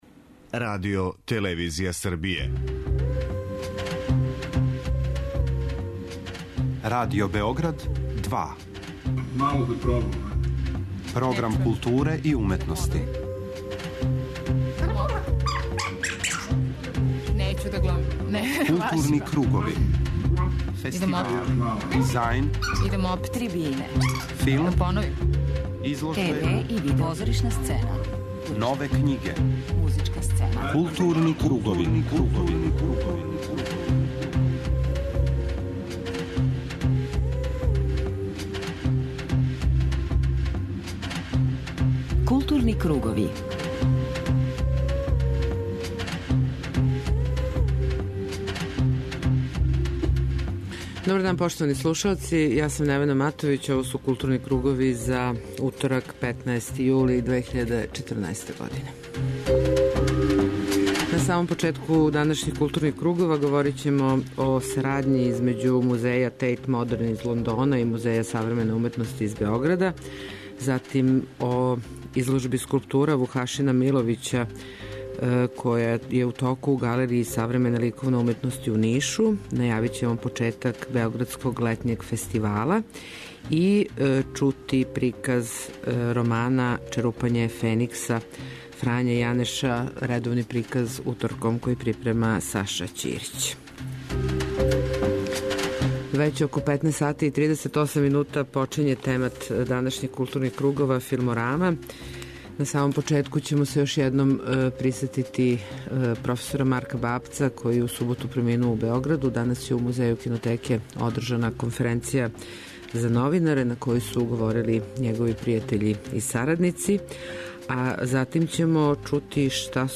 На почетку темата чућете назанимљивије делове излагања са јавног слушања на тему Ауторско право и колективно остваривање ауторских и сродних права драмских, филмских и телевизијских уметника и интерпретатора, које је одржано јуче у Народној скупштини Србије.